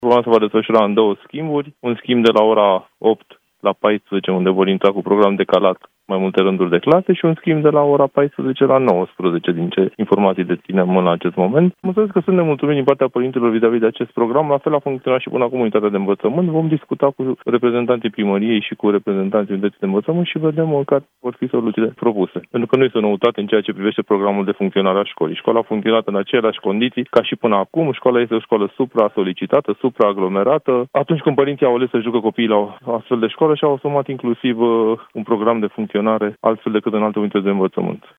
Iată ce le răspunde inspectorul general Florian Lixandru.